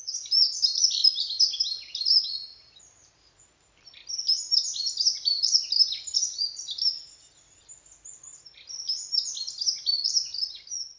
bird songs and sounds from the UK
Dunnock or Hedge Sparrow Get yourself familiar with this beautiful, simple little song before the Summer migrants arrive to cause confusion.
dunnock_2.mp3